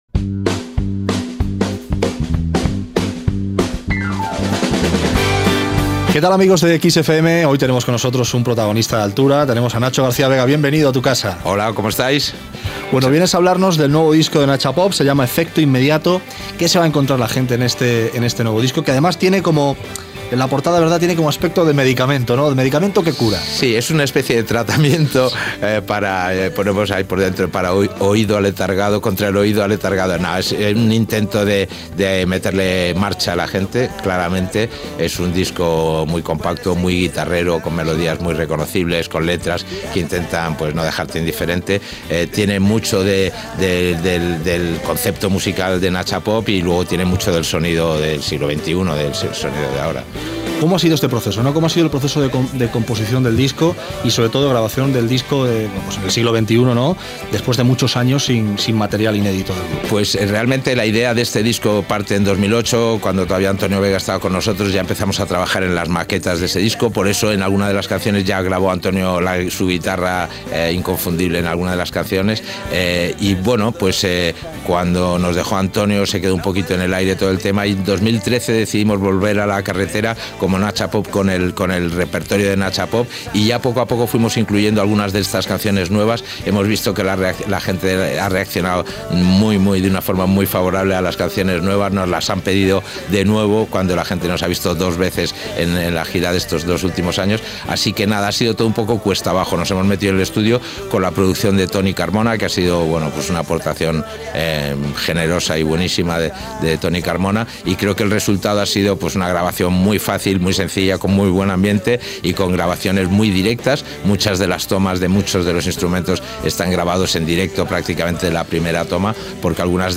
ENTREVISTA-NACHO-Gª-VEGA-PARTE-1.mp3